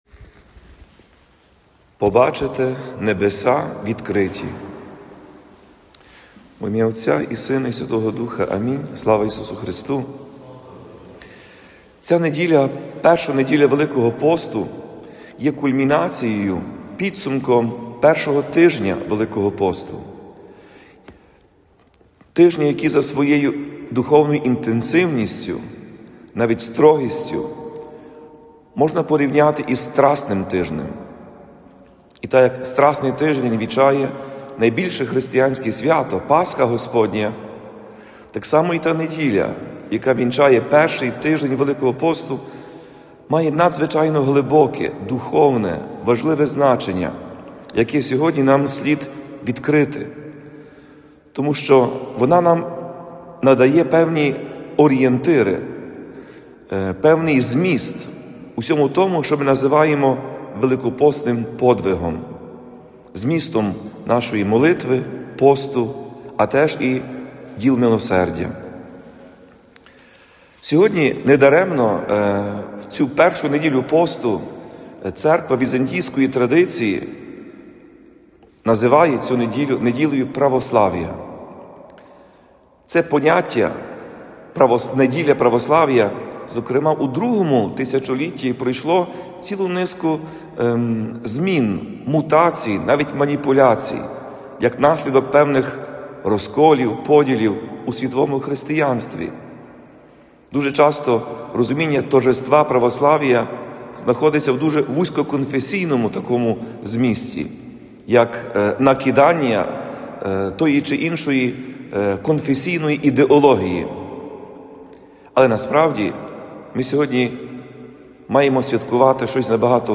Церква покликана передавати можливість особистої живої зустрічі з Христом, можливість живого богоспілкування. На цьому наголосив Отець і Глава УГКЦ Блаженніший Святослав під час проповіді в 1-шу неділю Великого посту.